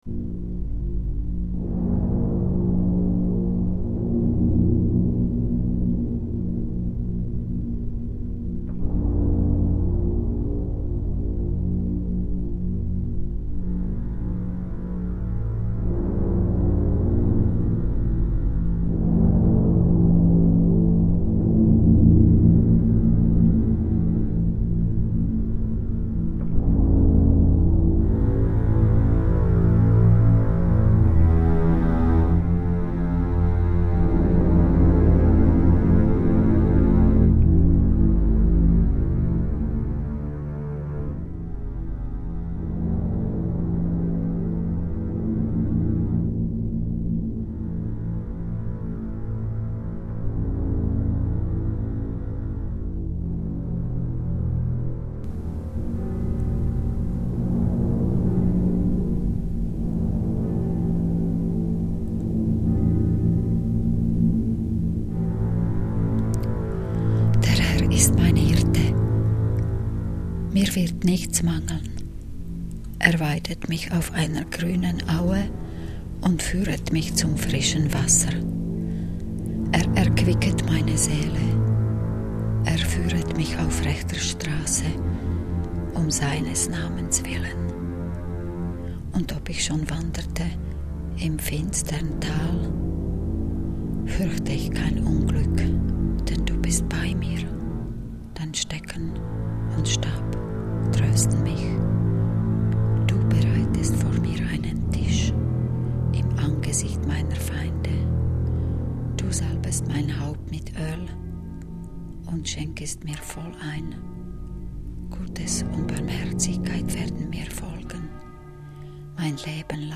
mit Rezitation